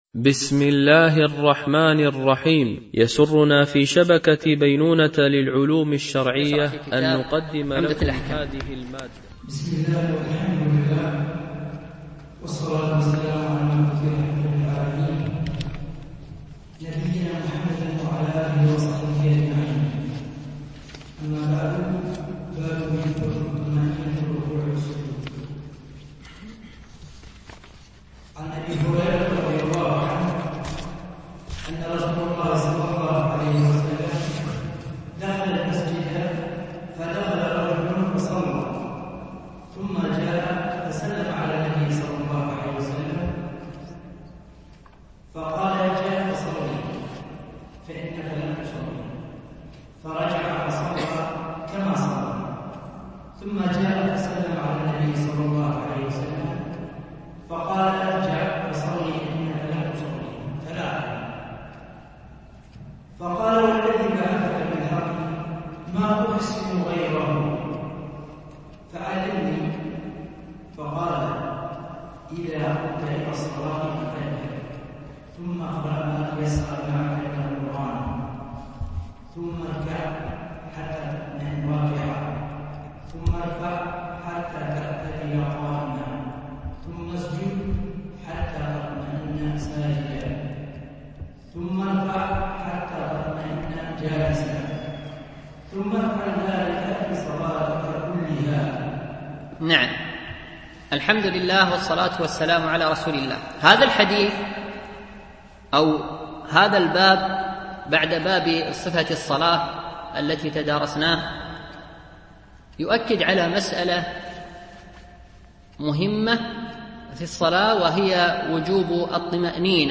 شرح عمدة الأحكام ـ الدرس الثامن والعشرون
MP3 Mono 22kHz 32Kbps (CBR)